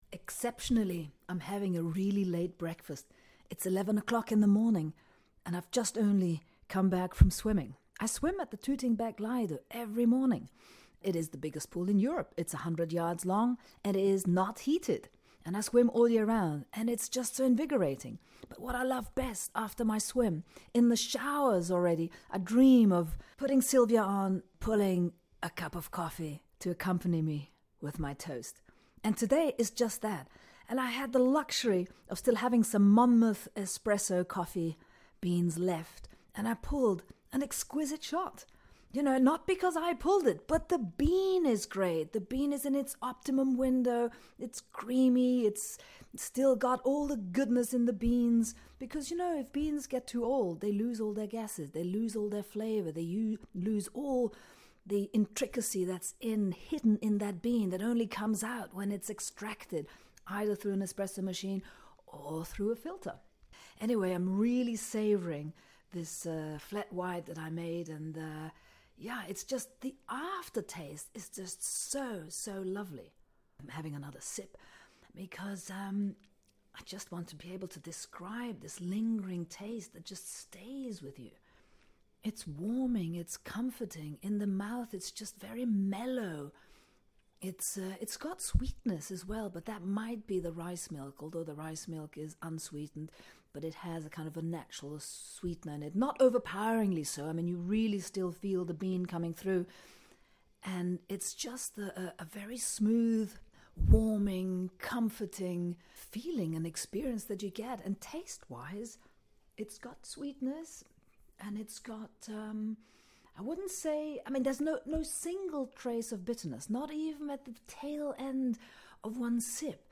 Savouring the moment a Monmouth Espresso Blend extraction served as a flat white here in my own home is pure luxury